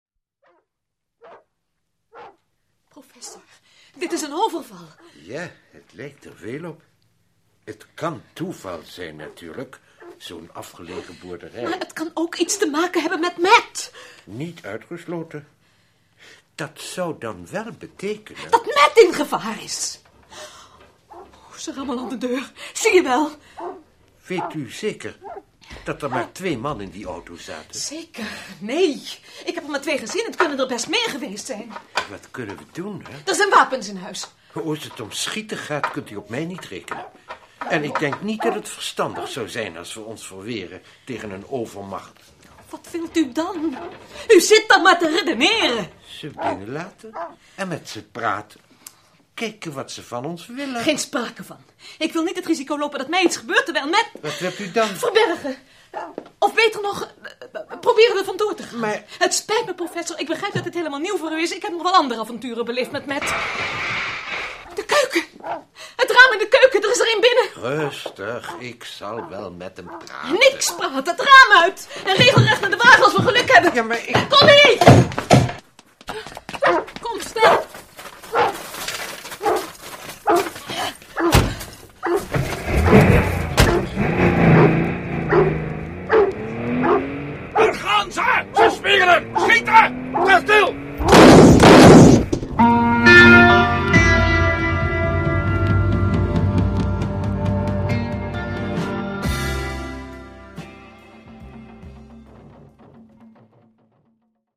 Wanneer Valerie bezoek krijgt van professor Marcus wordt de boerderij overvallen. De 2 overvallers hebben echter wel heel bekende stemmen.
Vervolgens hoor je duidelijk het geluid van ‘deuren’-slaan.